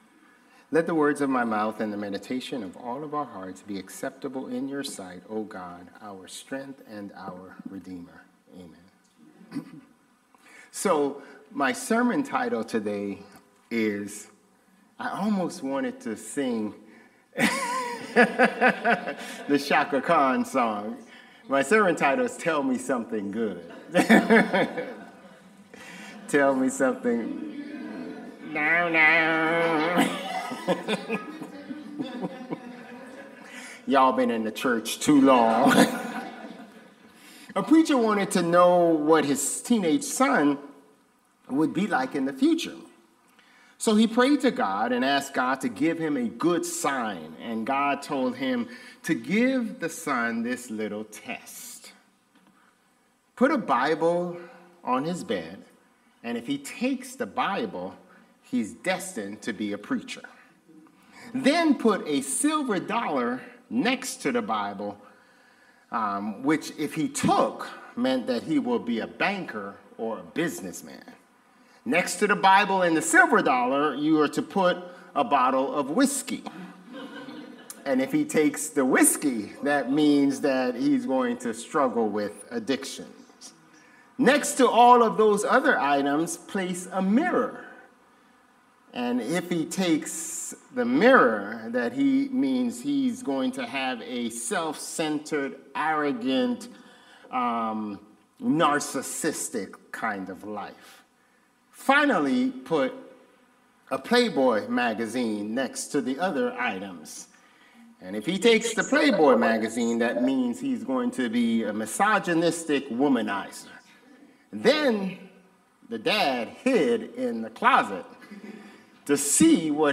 Sermons | Bethel Lutheran Church